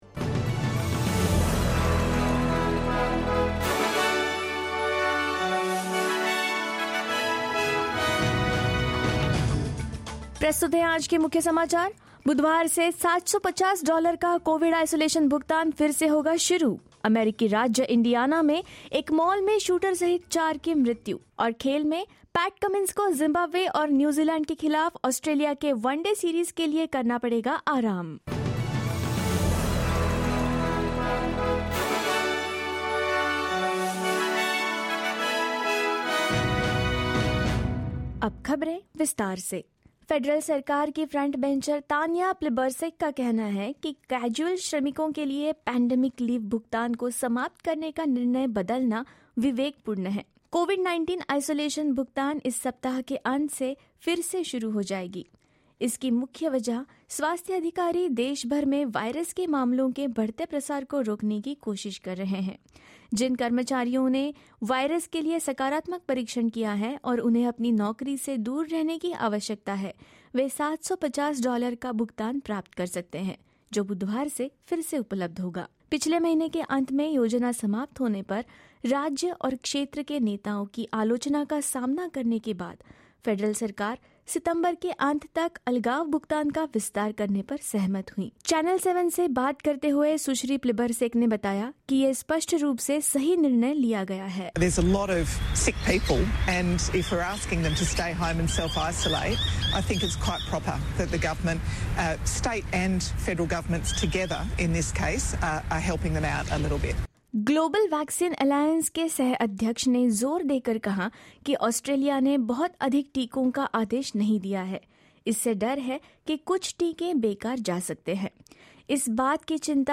In this latest SBS Hindi bulletin: Government to reinstate the Pandemic Leave Disaster Payment till the end of September; Four killed and two injured in a shooting at a mall in the US state of Indiana; Pat Cummins will be rested for Australia's One Day International series against Zimbabwe and New Zealand and more.